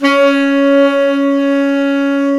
SAX_smc#4bx   17.wav